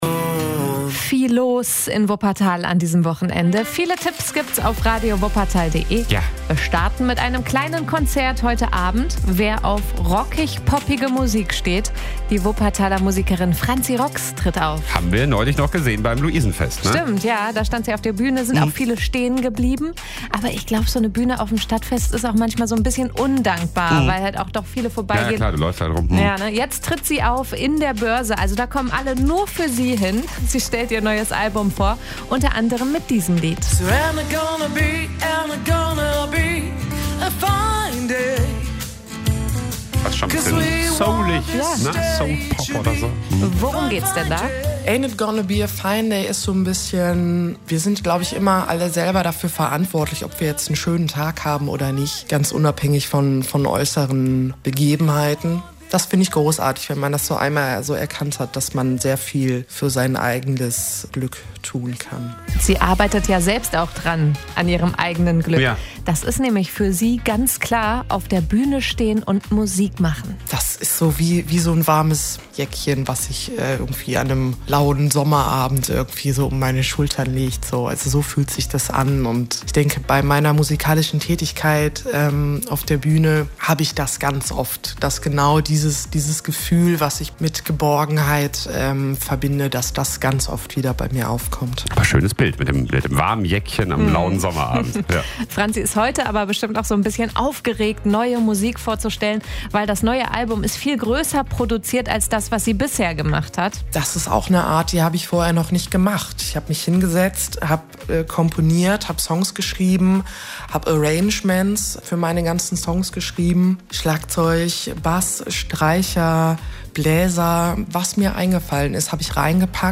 Rock/Pop.